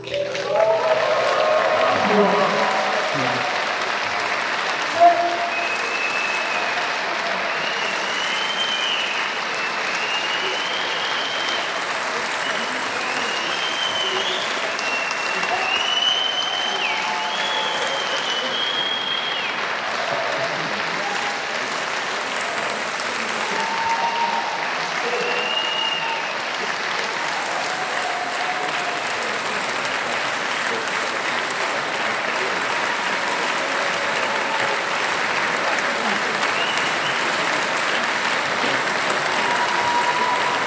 Konzert in der Kulturkirche Heilig Kreuz
Applaus!
applaus.mp3